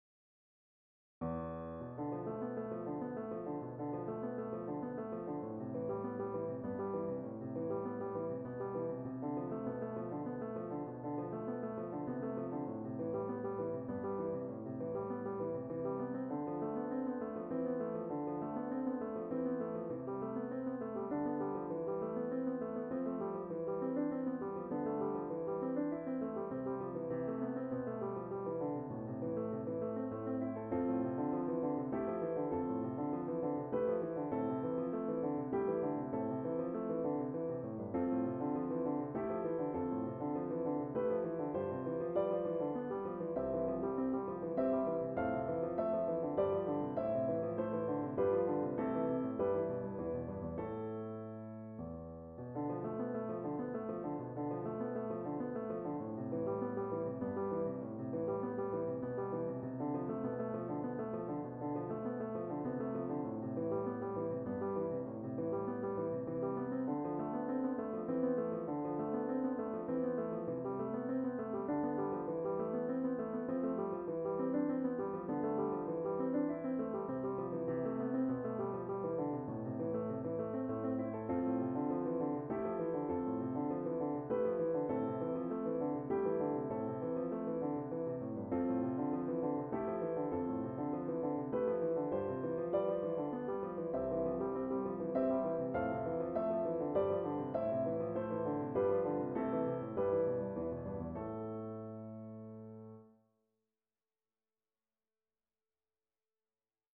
Since these are all meant to be brief bagatelles, I figured it was an easier way to pick up my pencil than, say, trying to finish Seven Dreams of Falling.
So here we are, in 2023, and I’ve written a small waltz, “Waltz G.”